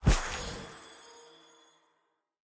Minecraft Version Minecraft Version 25w18a Latest Release | Latest Snapshot 25w18a / assets / minecraft / sounds / block / trial_spawner / spawn_item_begin1.ogg Compare With Compare With Latest Release | Latest Snapshot
spawn_item_begin1.ogg